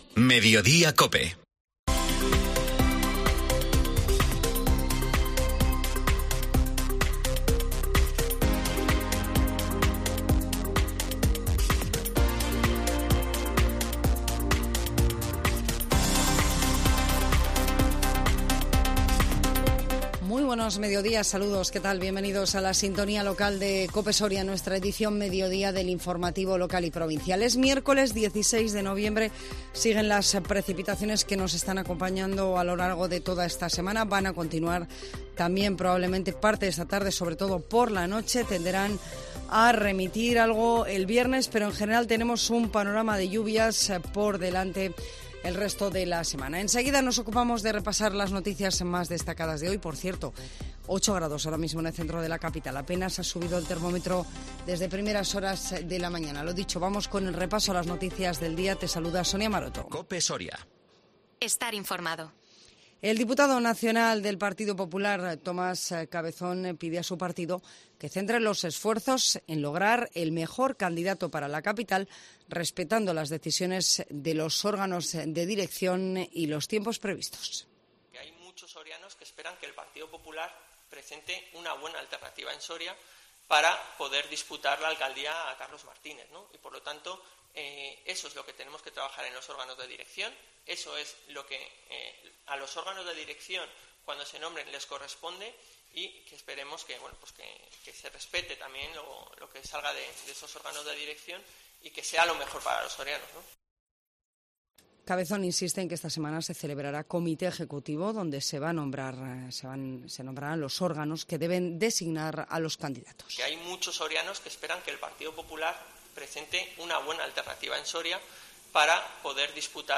INFORMATIVO MEDIODÍA COPE SORIA 16 NOVIEMBRE 2022